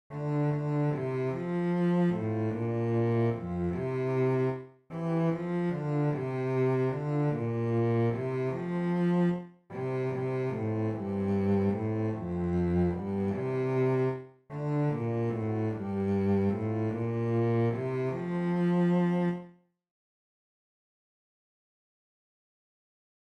Chorproben MIDI-Files 496 midi files